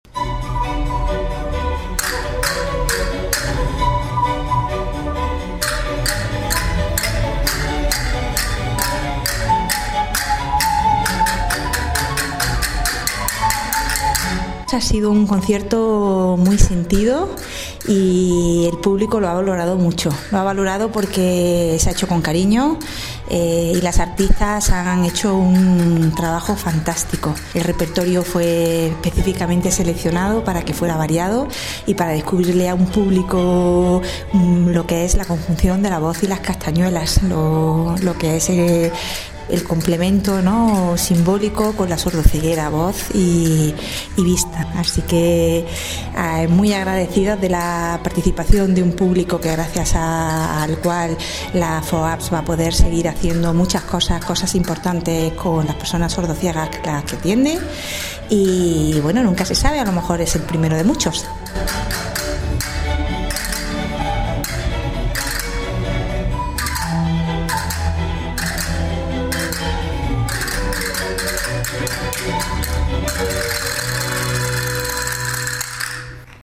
La escuchamos formato MP3 audio(1,46 MB) con el fondo de su acompañamiento con castañuelas de los Conciertos de Bach, con el que se cerró el recital.